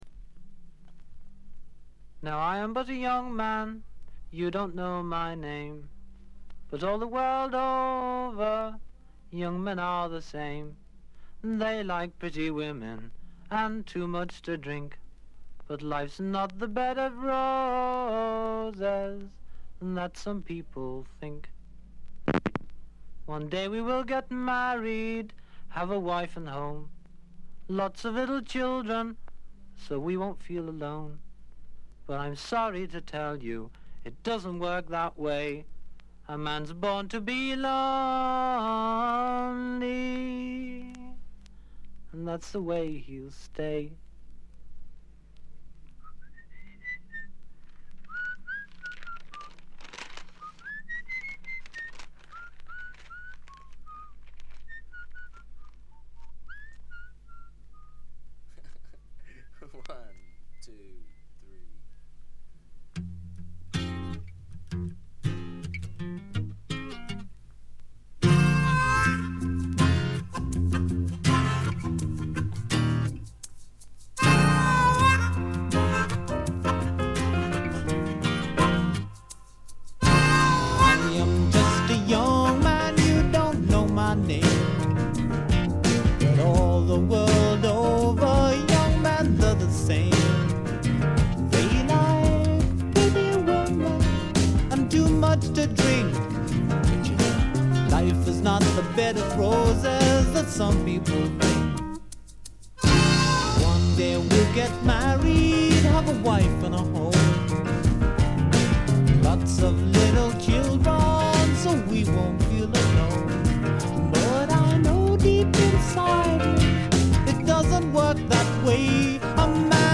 B1冒頭無伴奏部でビリつき。
試聴曲は現品からの取り込み音源です。